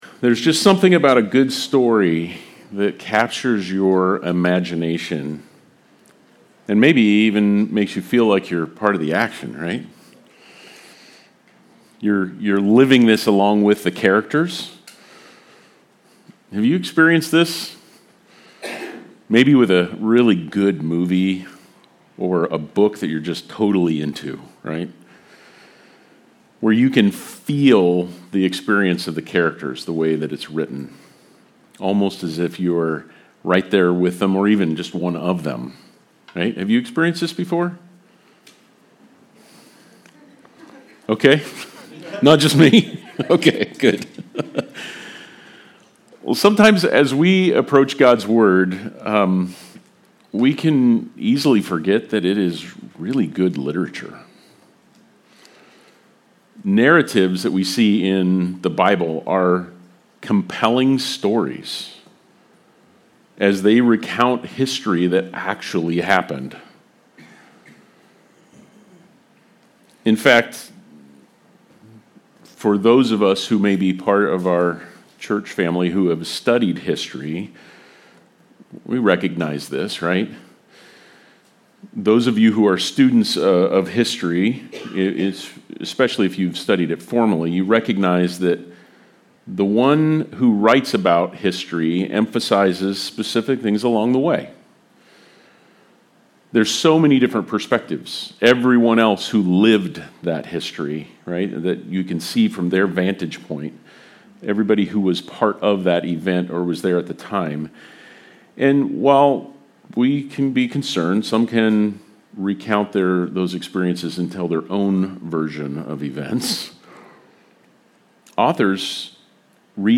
Luke 9:1-17 Service Type: Sunday Service Related « Desperate Times